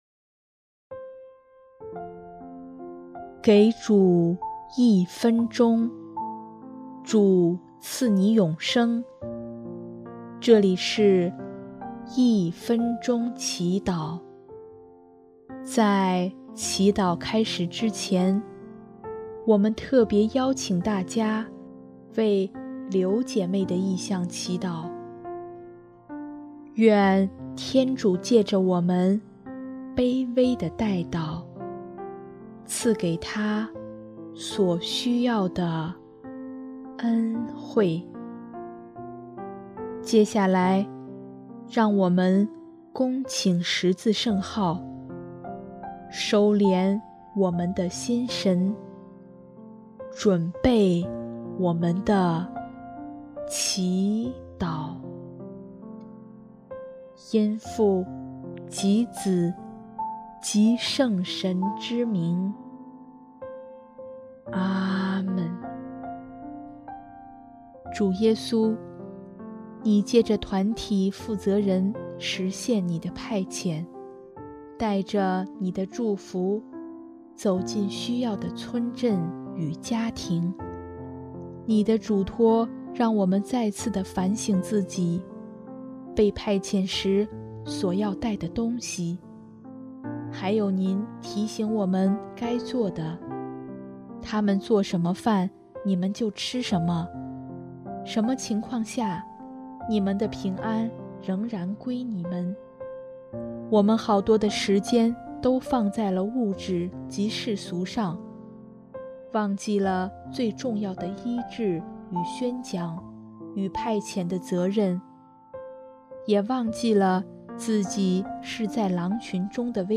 【一分钟祈祷】|10月3日 派遣与责任